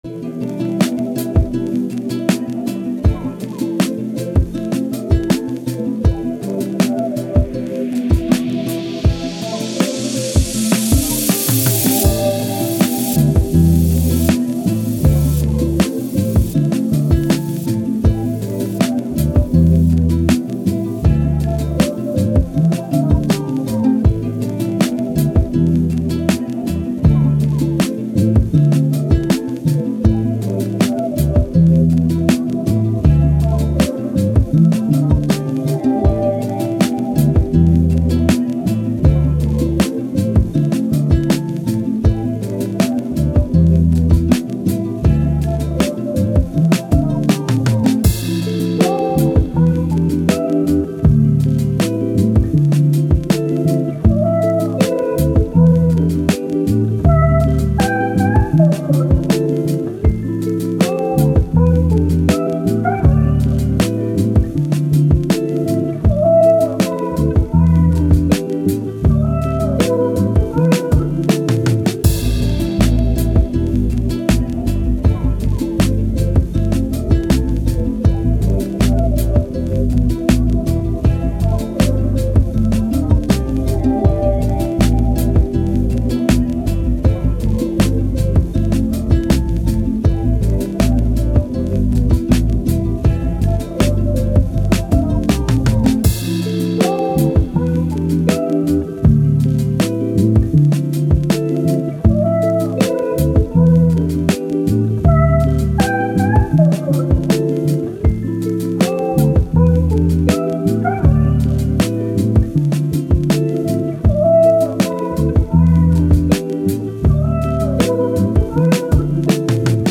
Hip Hop, Soul, Mix, Chill, Positive